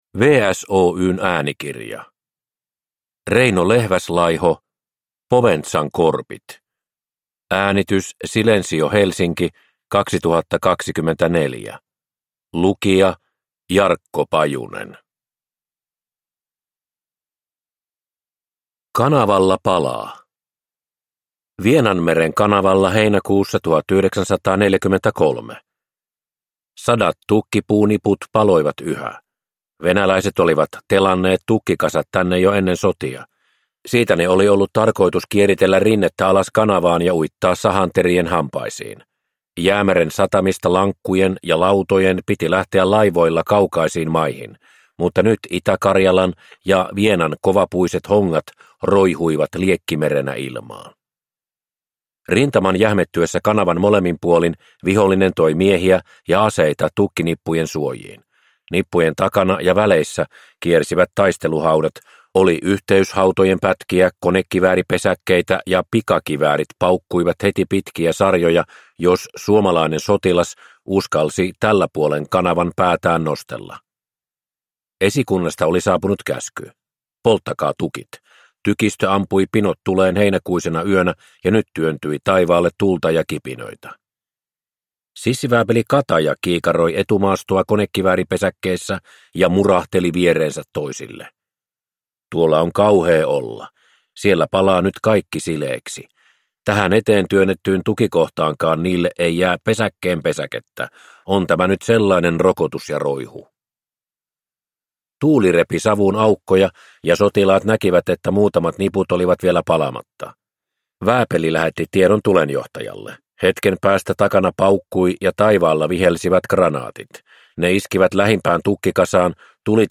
Poventsan korpit (ljudbok) av Reino Lehväslaiho